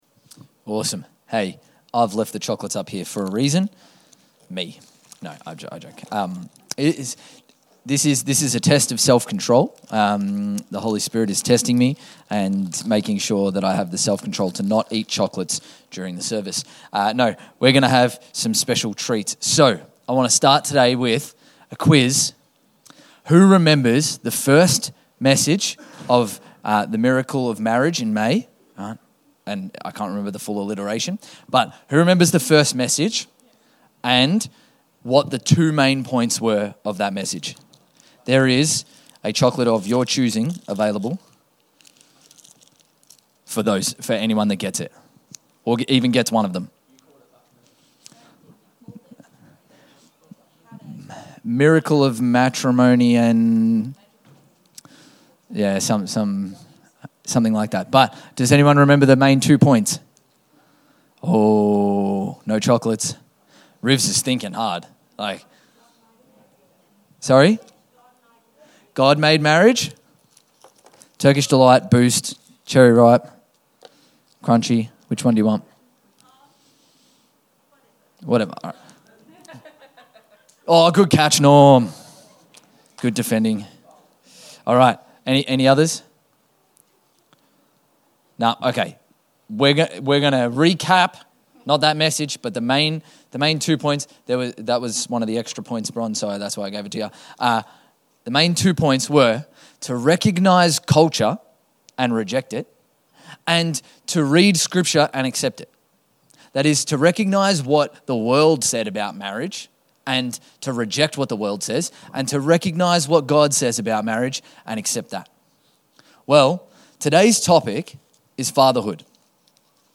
Infinity Church Podcast - English Service | Infinity Church